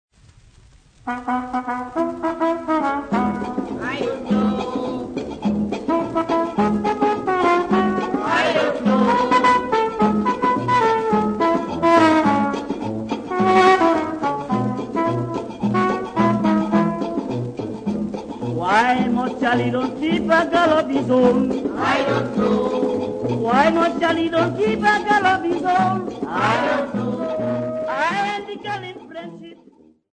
Popular music--Africa
Dance music
Dance music--Caribbean Area
Calypso dance band song
96000Hz 24Bit Stereo